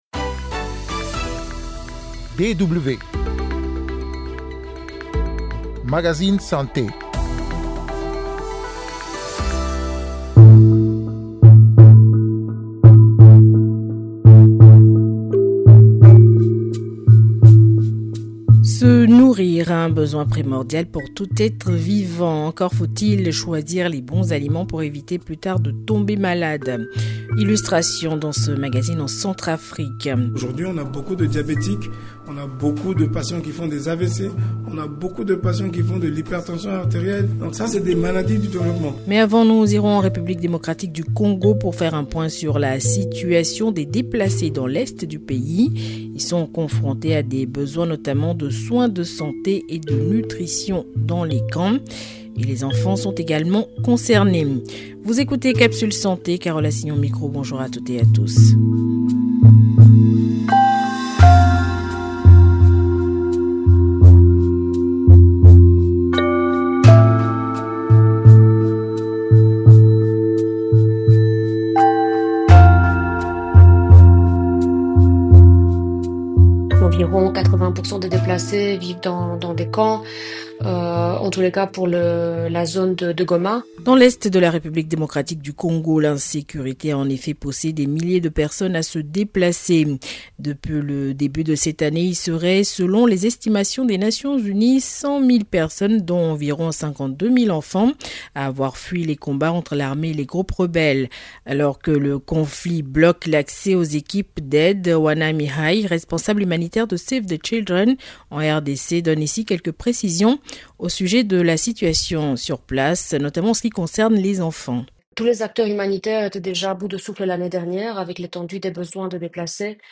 A l’aide de reportages et d’interviews avec des spécialistes, ce magazine propose une information concrète et vivante sur les grands thèmes de santé.